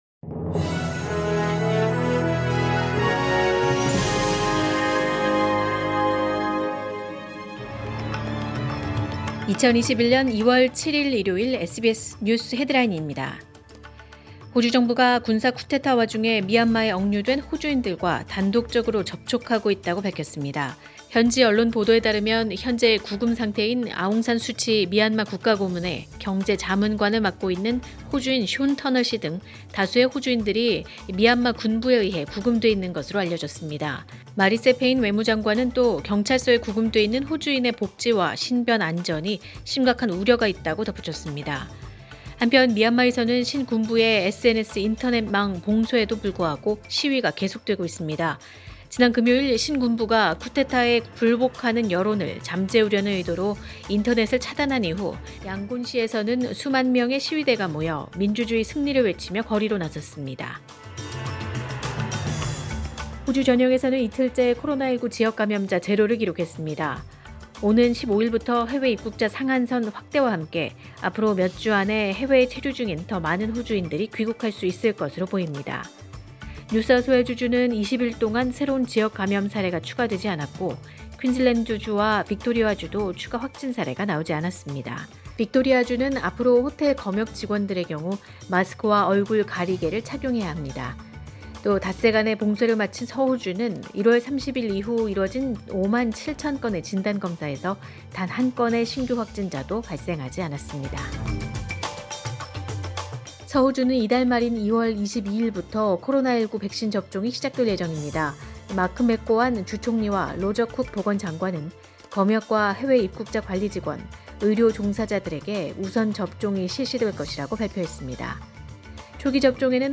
2021년 2월 7일 일요일 SBS 뉴스 헤드라인입니다.